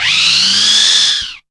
POWER SCREAM.wav